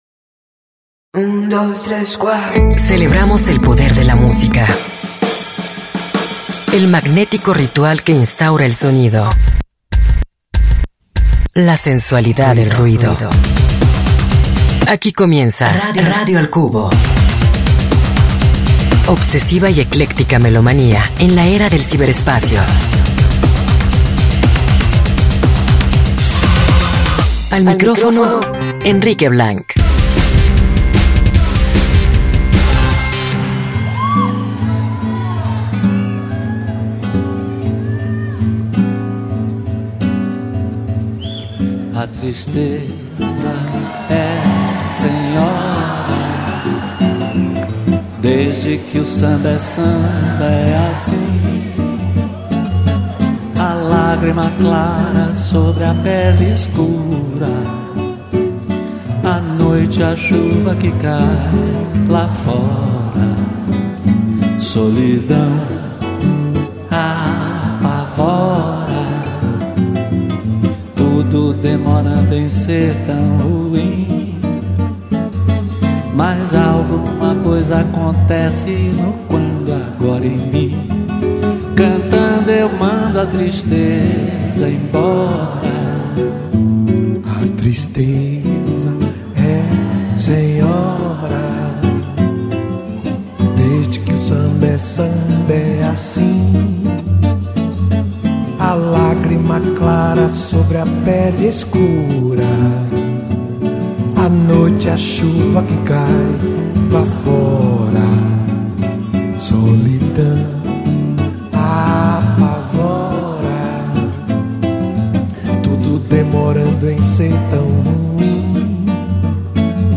la mejor musica para iniciar la semana